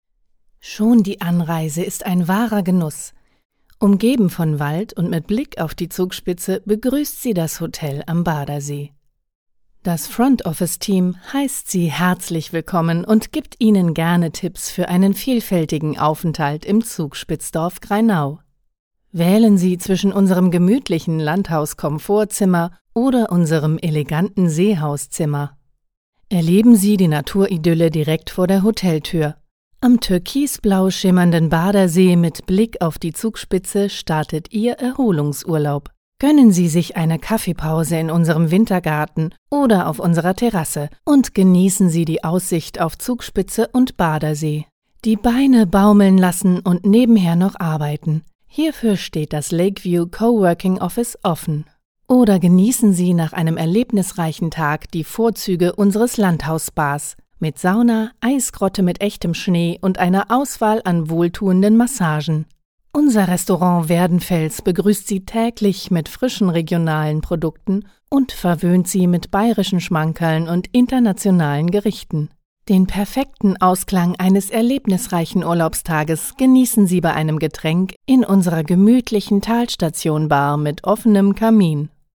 Frische, helle, warme Stimme für E-Learning, Werbung, Guides und Imagefilme.
Sprechprobe: Sonstiges (Muttersprache):
Fresh, light, warm voice for e-learning, advertorials, guides and corporate films.